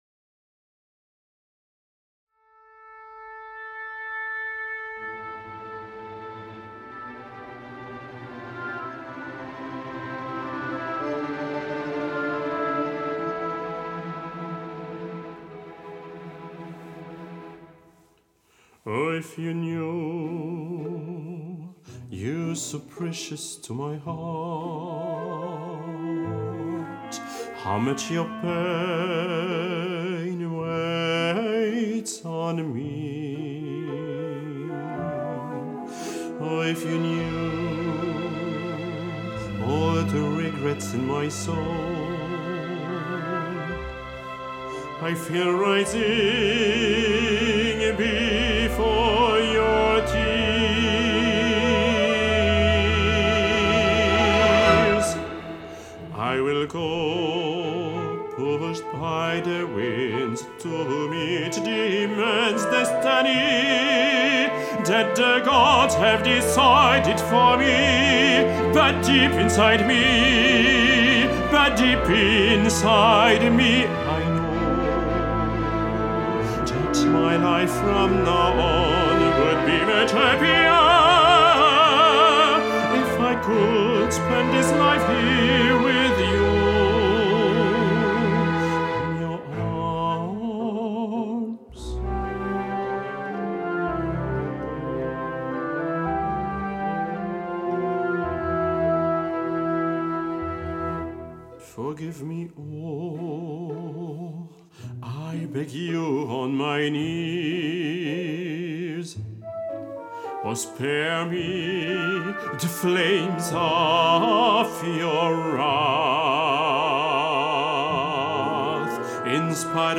barytone